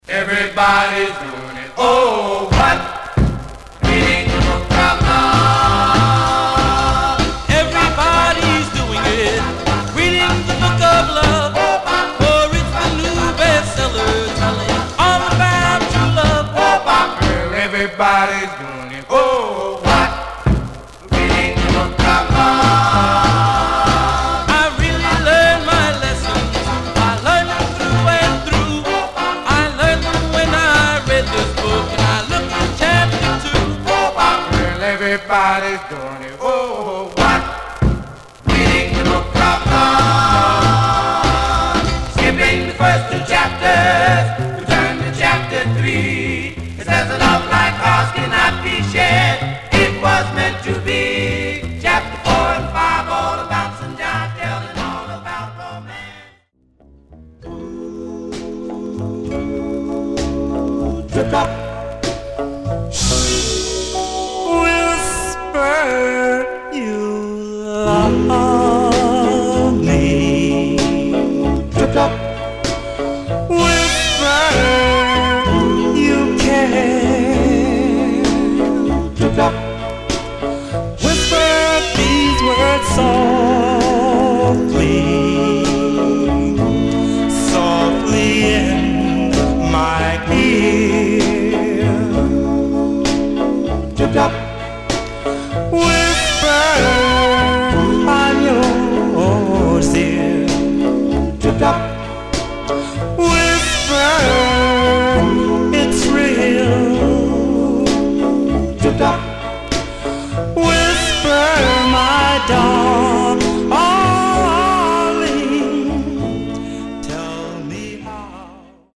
Male Black Groups